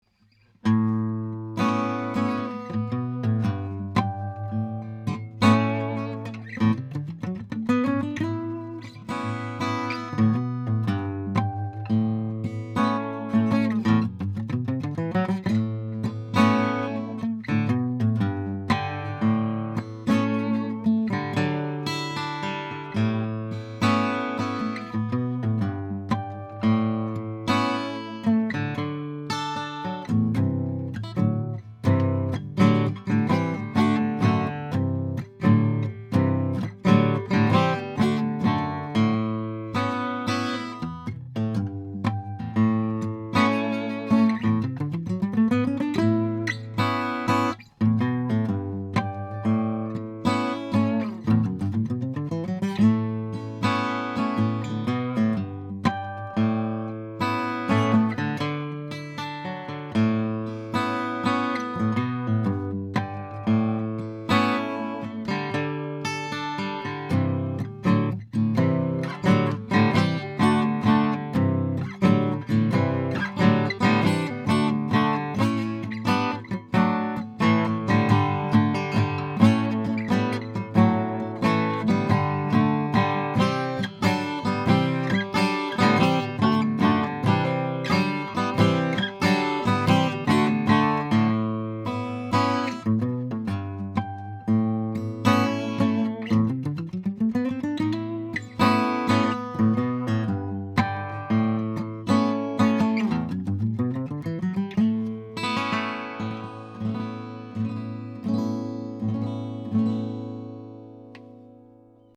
Vintage Sennheiser MD421-U5 Dynamic Mic
Tracked through a Warm Audio WA12 preamp into a Metric Halo ULN8 interface:
1933 MARTIN 0-17 FLATOP
* Smooth Response